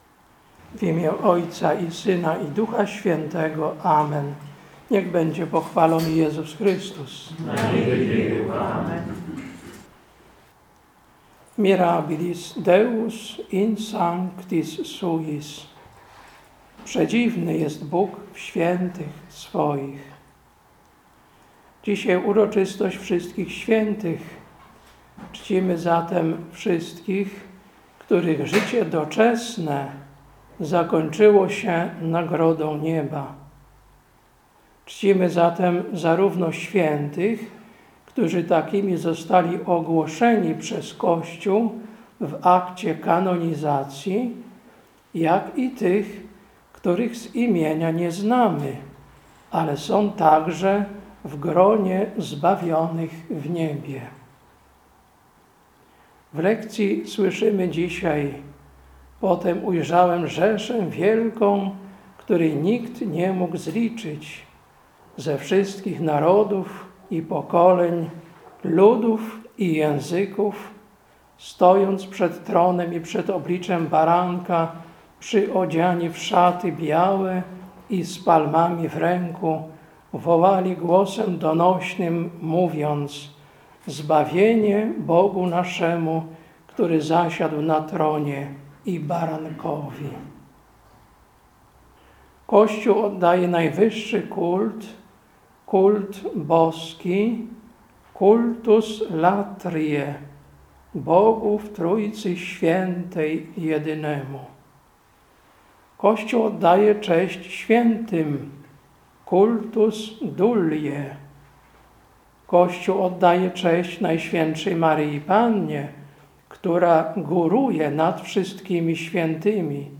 Kazanie na uroczystość Wszystkich Świętych, 1.11.2022
Kazanie na uroczystość Wszystkich Świętych, 1.11.2022. Msza Święta w rycie rzymskim.